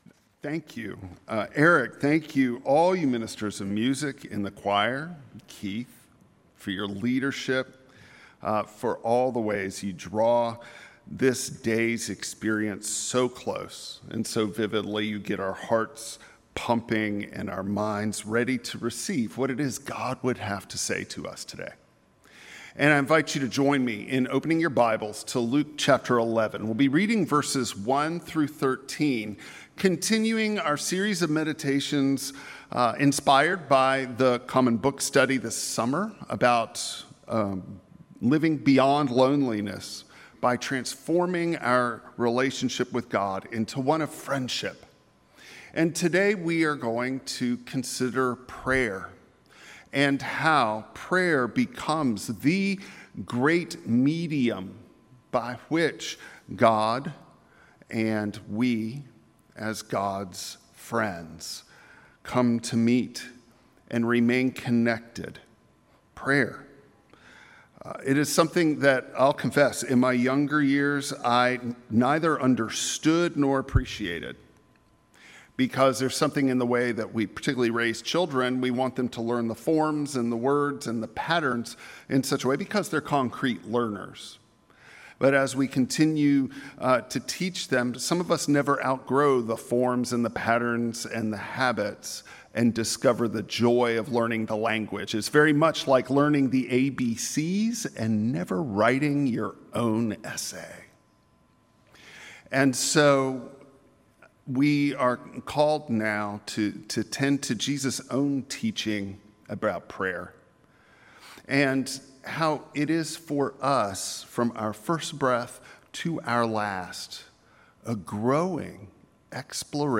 Luke 11:1-13 Service Type: Traditional Service A life of prayer isn’t about getting answers